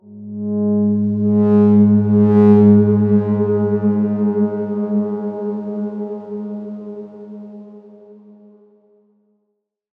X_Darkswarm-G#2-ff.wav